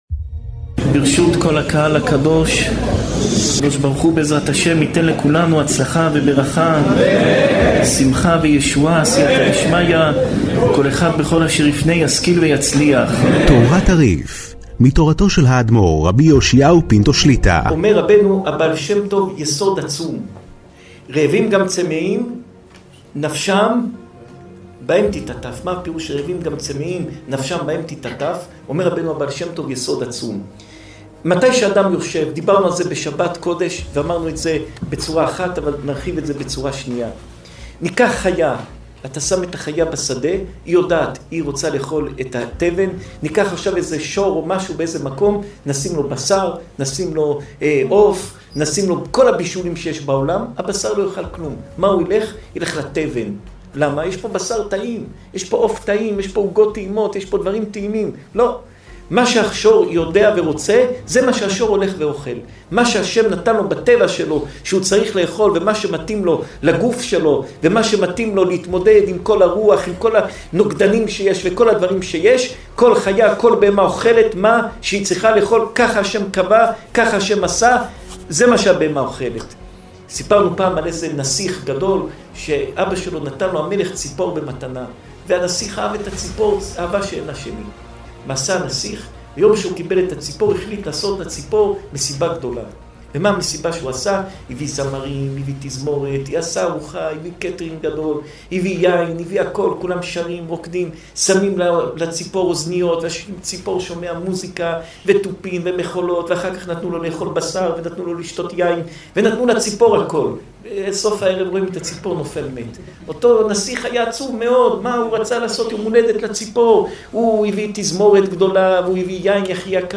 שעור תורה מפי הרב פינטו